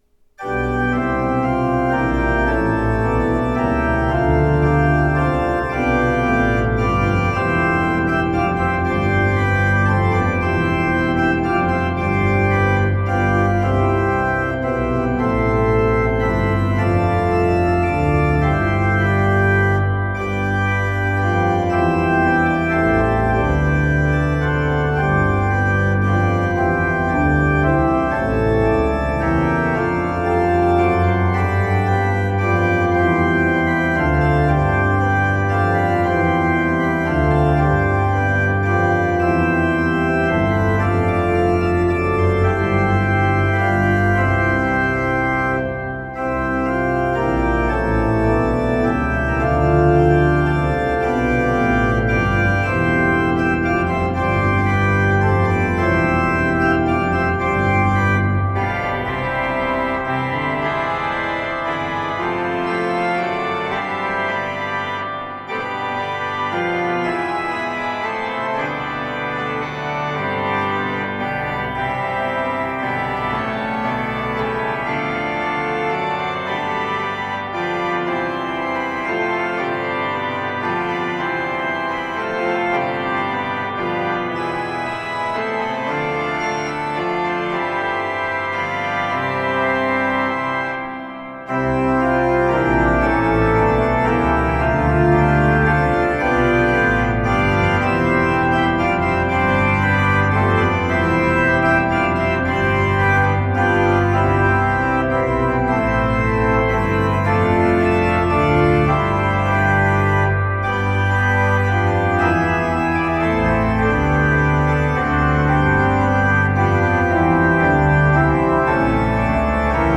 This setting of “The Morning Breaks” was written as a postlude for the outgoing missionary devotional of the California San Jose Mission.